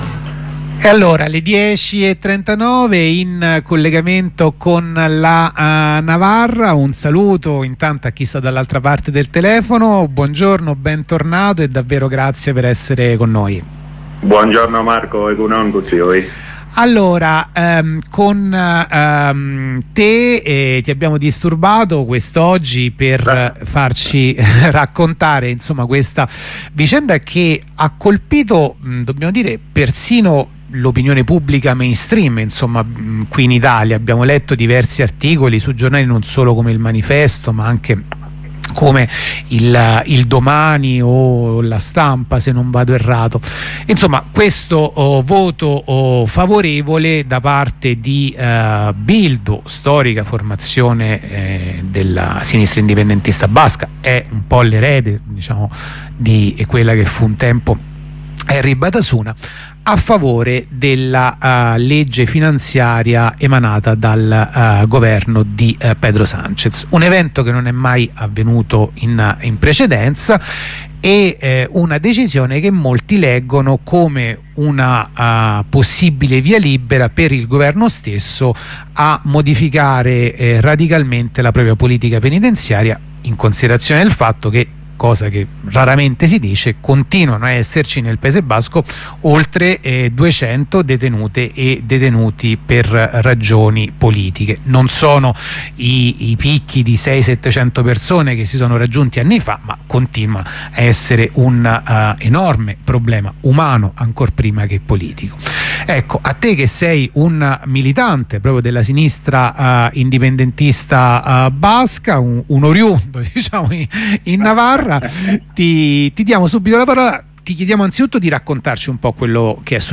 Prima corrispondenza da piazza Indipendenza